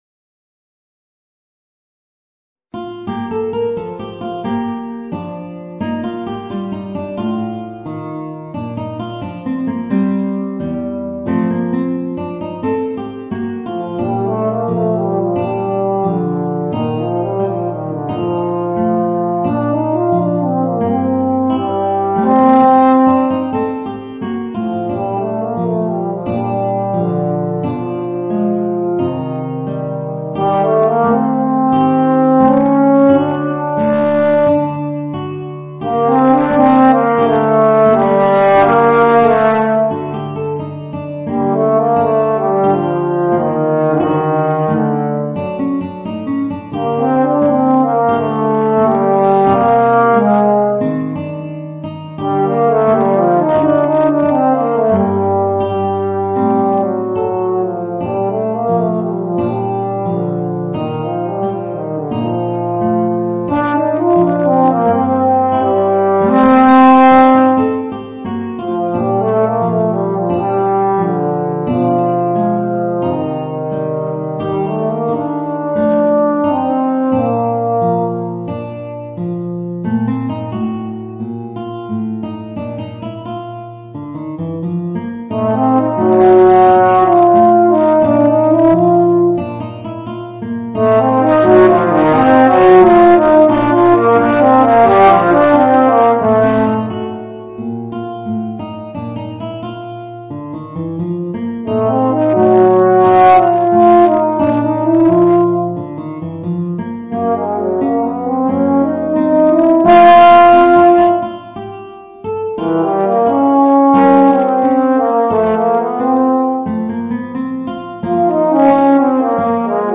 Voicing: Euphonium and Guitar